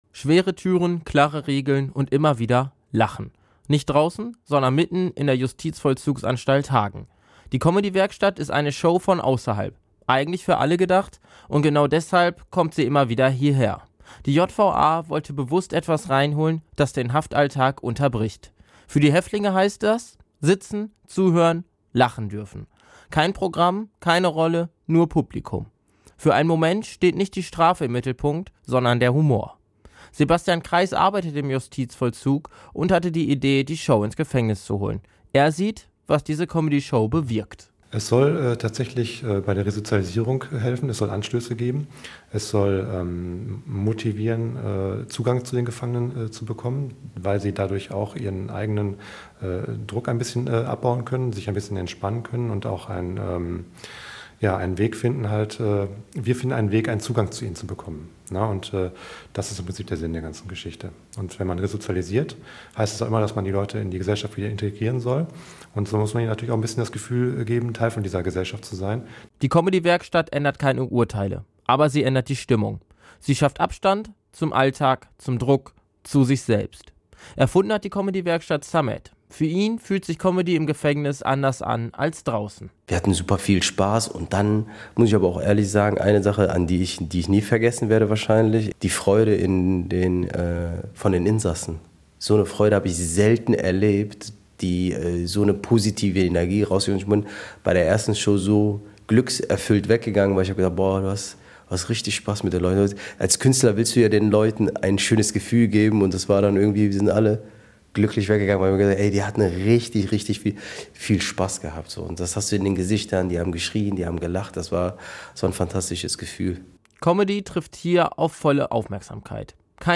beitrag-comedywerkstatt-in-der-jva.mp3